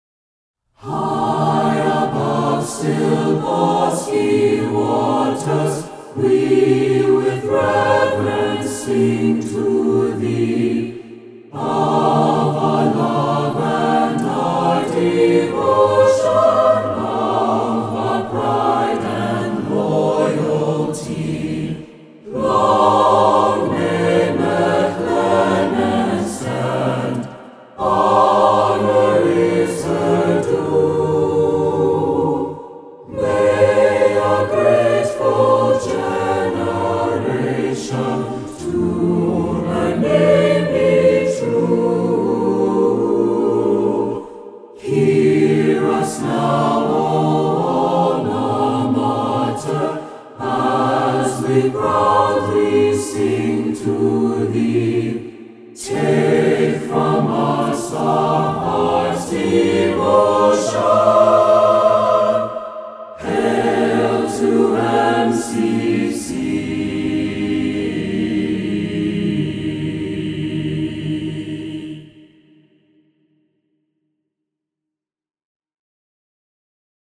Performed by the MCC Chorale